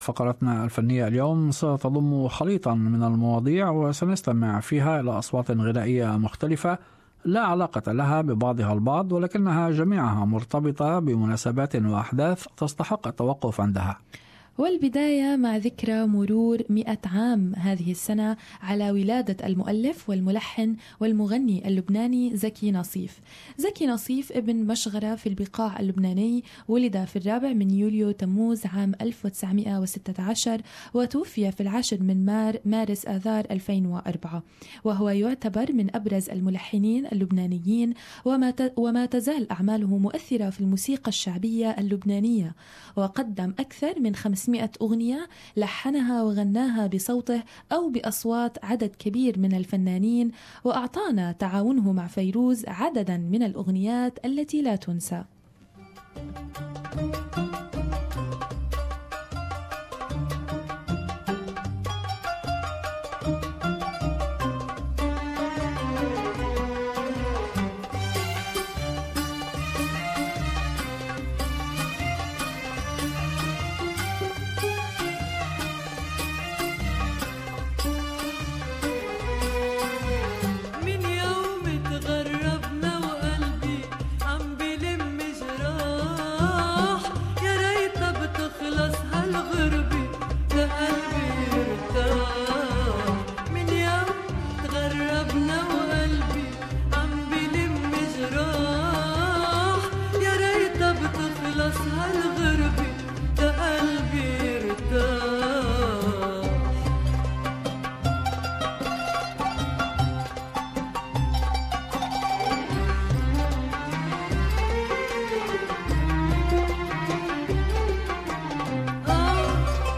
Mix of Arabic songs